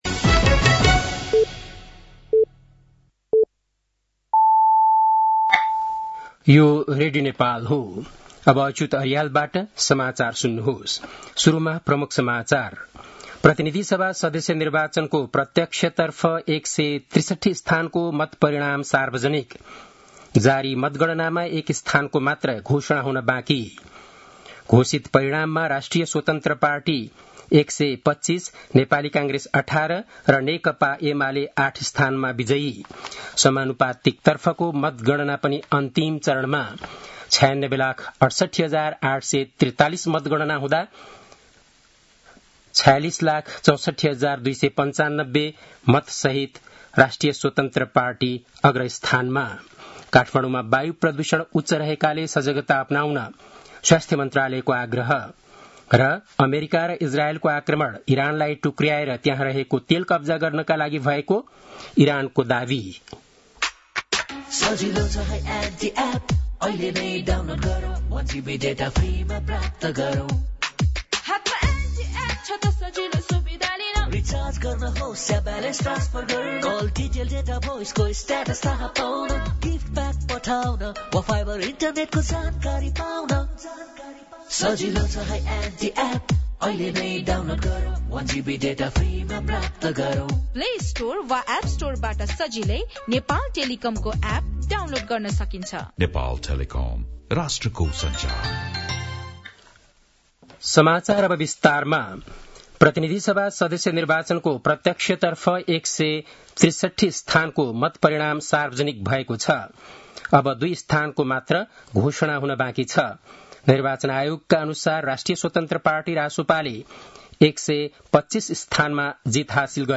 बेलुकी ७ बजेको नेपाली समाचार : २५ फागुन , २०८२